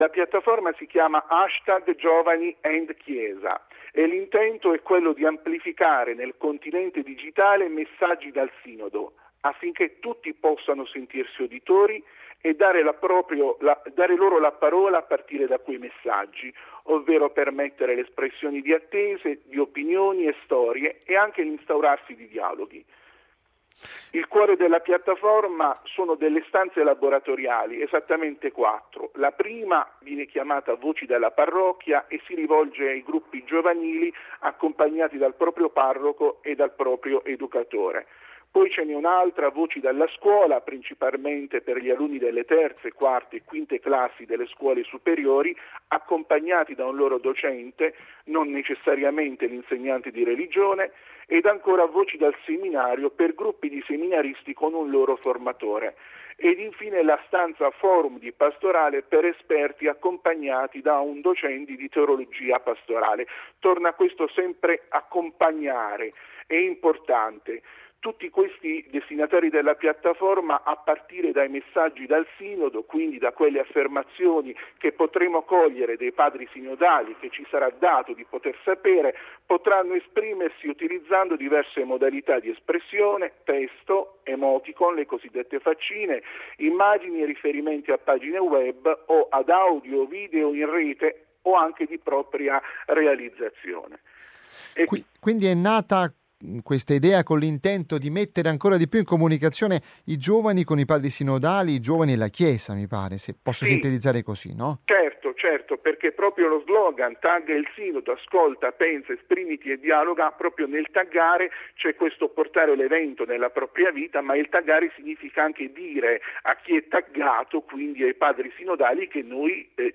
VATICAN NEWS – Intervista, 4 ottobre